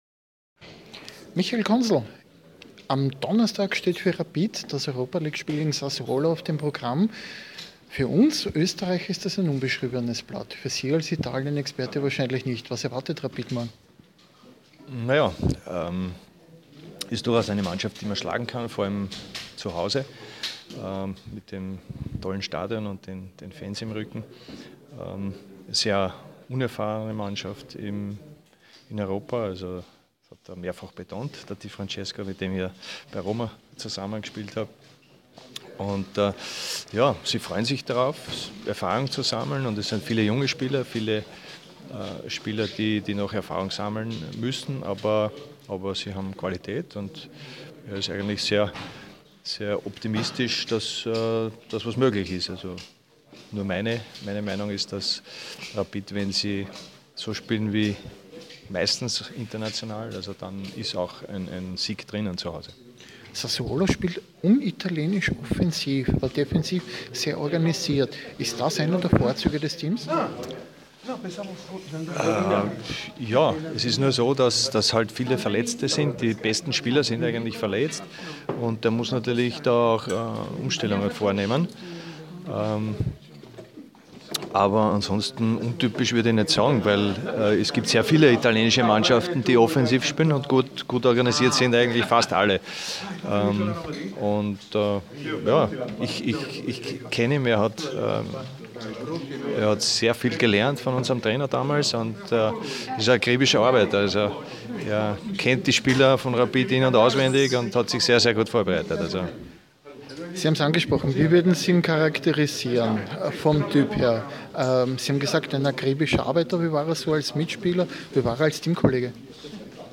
Michael Konsel im Interview vor Rapid vs. Sassuolo